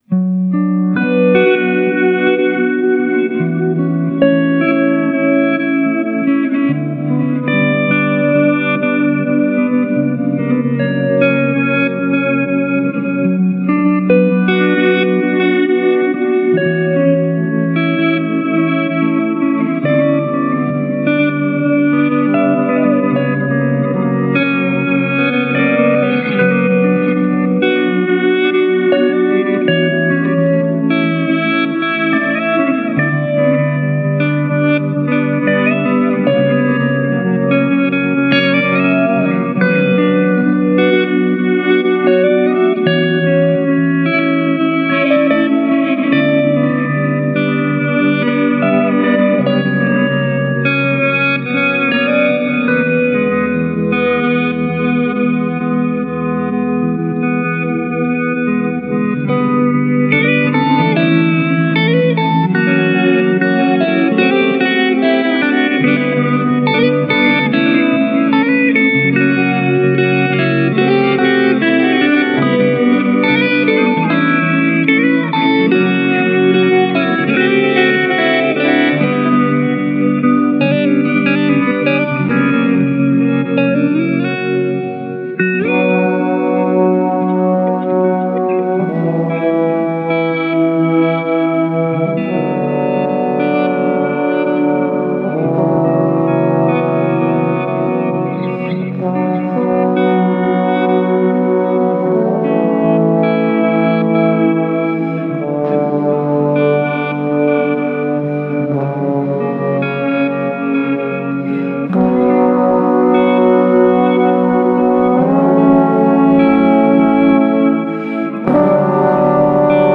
Sustained six string mutations.
Sad emotional guitar theme with hopeful build.